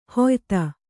♪ hoyta